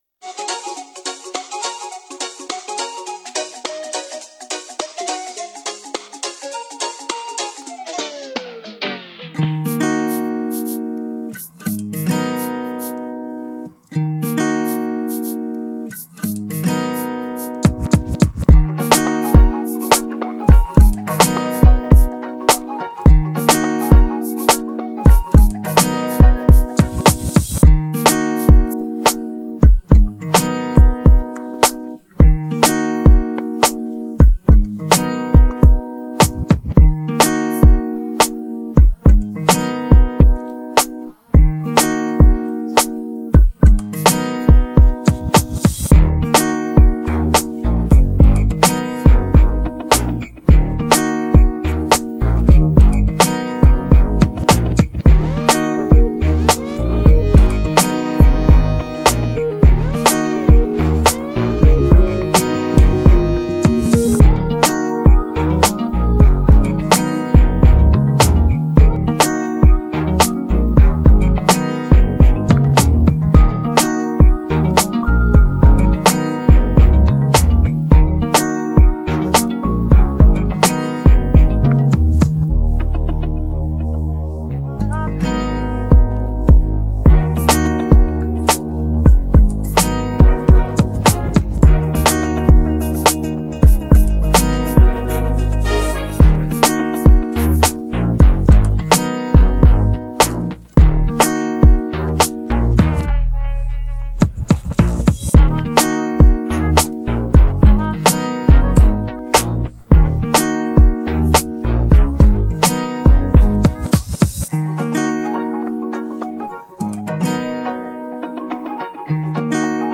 Highlife
Tagged afrobeats